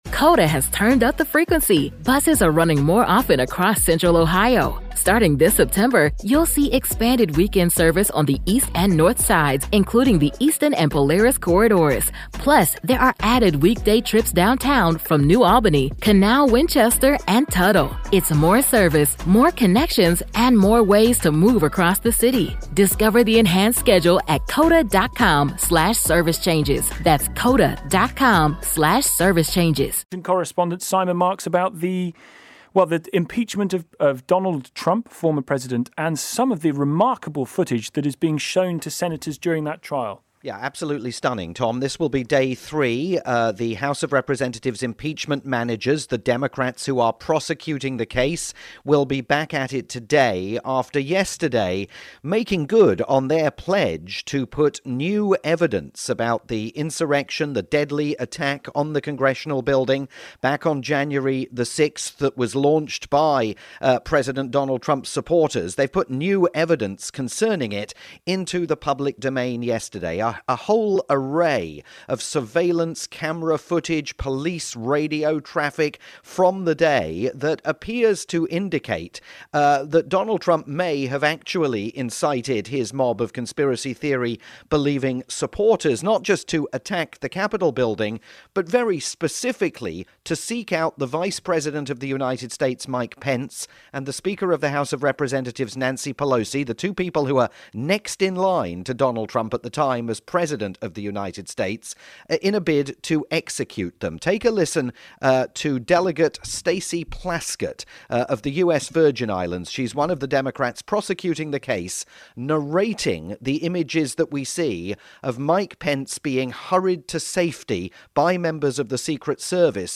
My live report